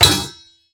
metal_lid_movement_impact_10.wav